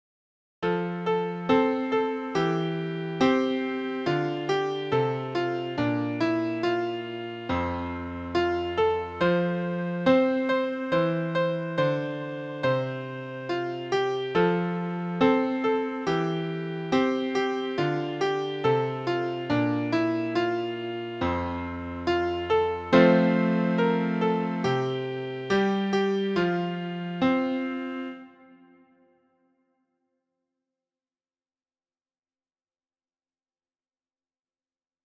traditional songs
for the harp